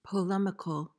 PRONUNCIATION:
(puh-LEM-uh-kuhl)